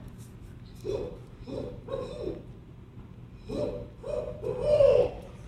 He taught the audience coyote, wolf, and owl calls, then suggested everyone try those animal calls in their own time and see if they get a response.